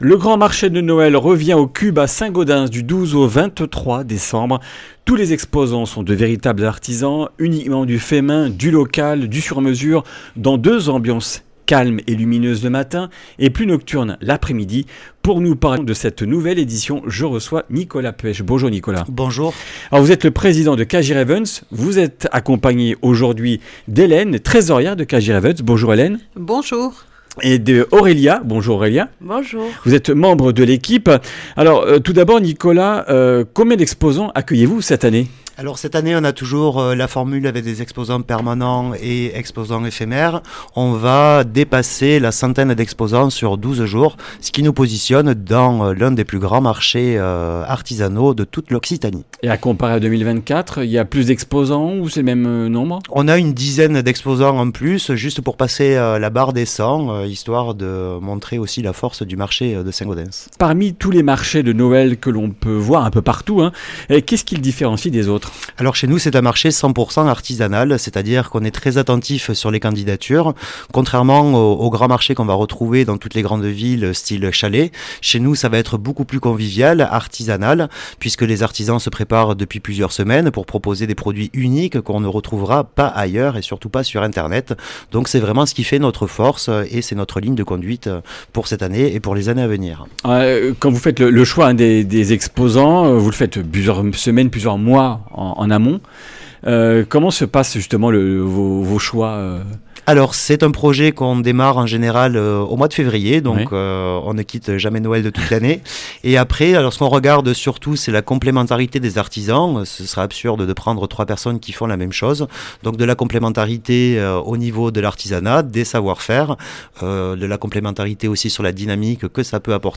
Comminges Interviews du 15 déc.
Une émission présentée par
Journaliste